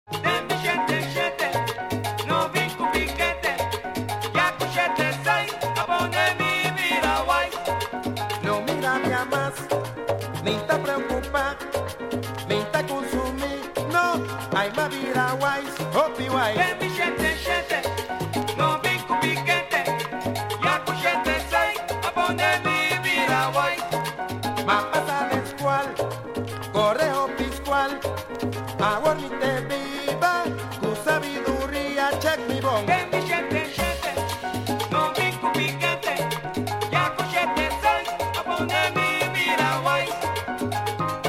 1977 Género: Latin Estilo: Salsa, Pachanga